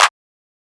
Clap (Atlanta).wav